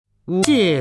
方明朗诵：《乌衣巷》(（唐）刘禹锡) （唐）刘禹锡 名家朗诵欣赏方明 语文PLUS